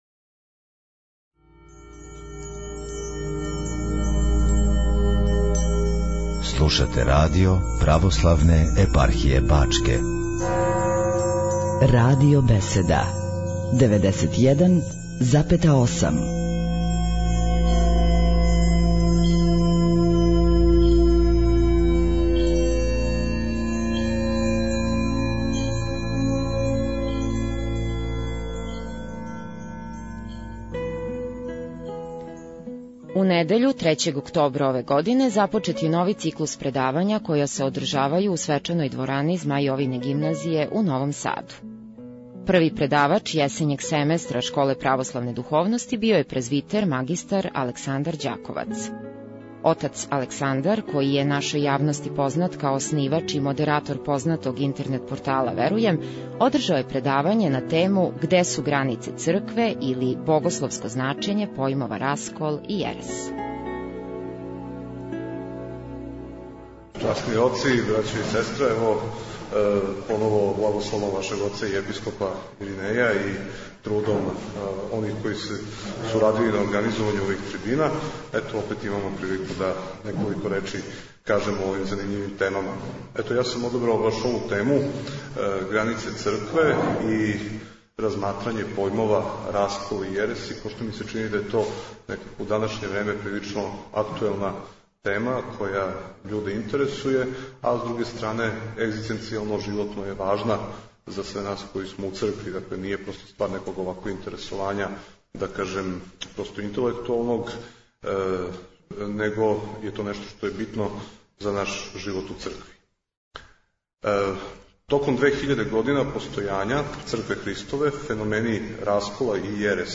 У недељу, 3. октобра 2010. године, започет је нови циклус предавања која се одржавају у свечаној дворани Змај Јовине Гимназије у Новом Саду.